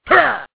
One of Donkey Kong's voice clips in Mario Kart DS